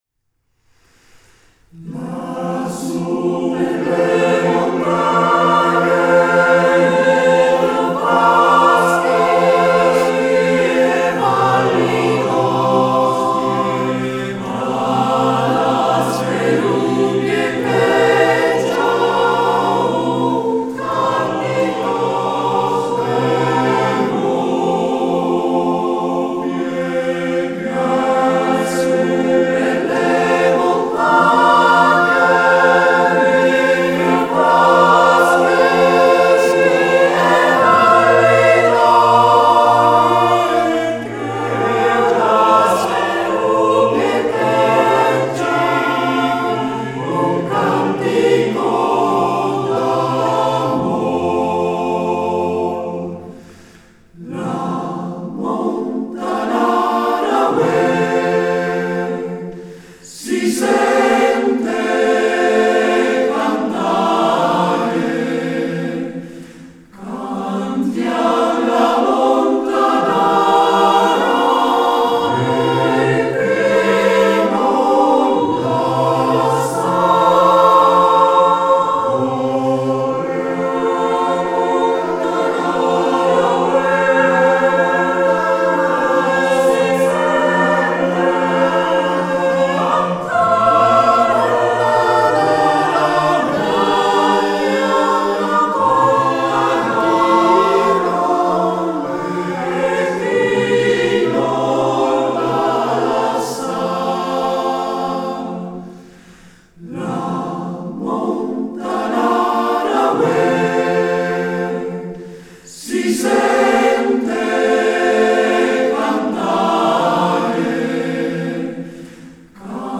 Legnago (VR) 07 maggio 2016